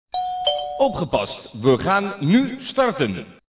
Start dingdong
Jingle-01-Start ding dong.mp3